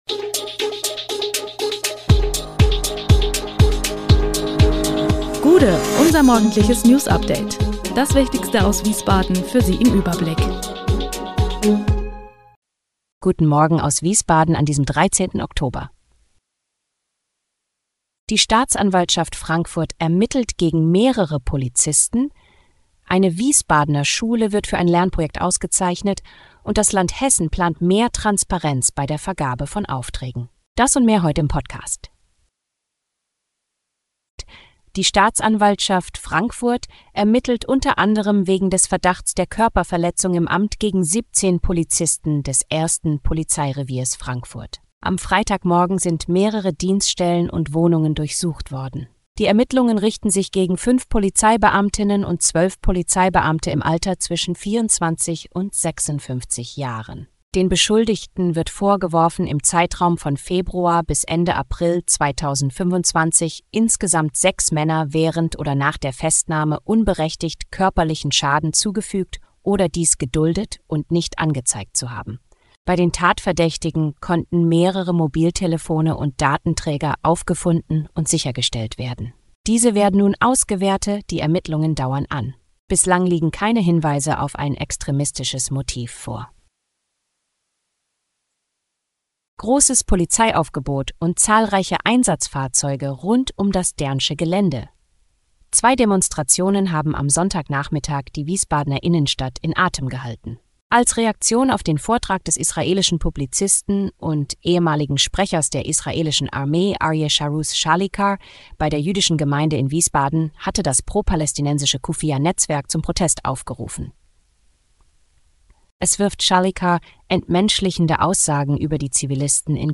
Der Podcast am Morgen für die Region
Nachrichten